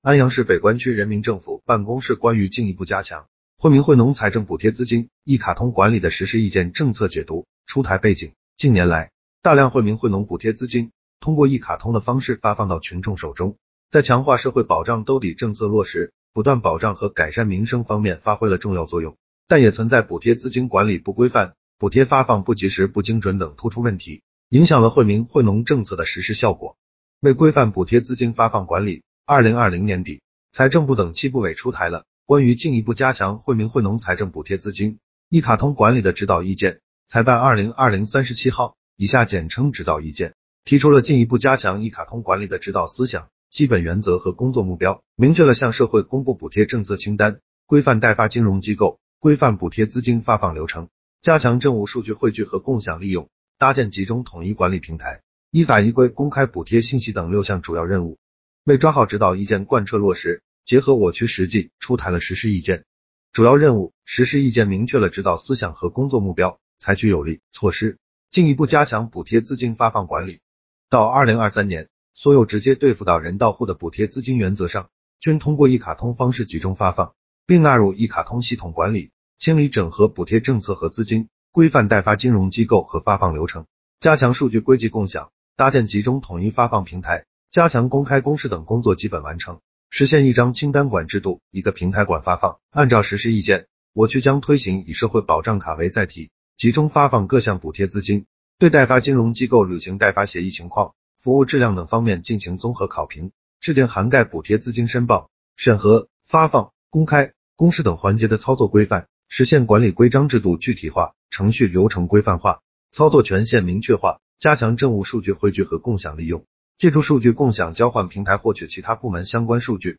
音频解读